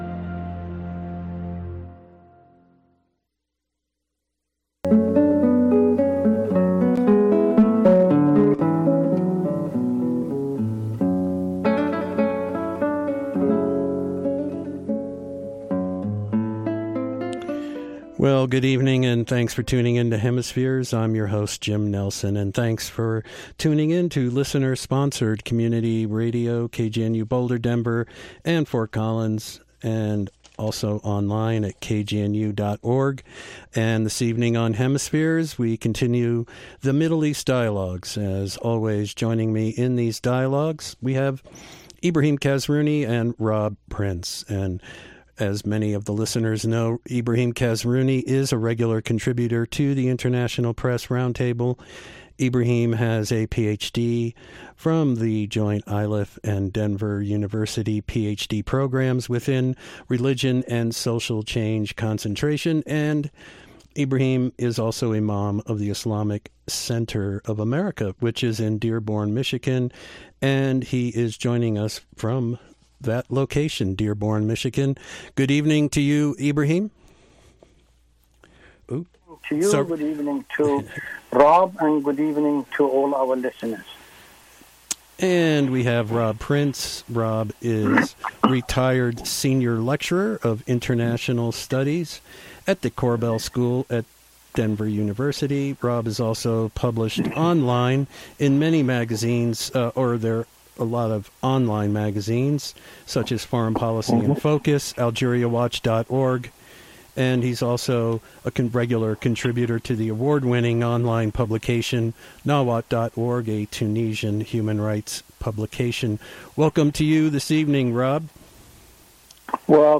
Shifting Tides of War from Syria To Libya.”KGNU 1390 AM, 88.5 FM